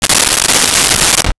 Shower Curtain Close 01
Shower Curtain Close 01.wav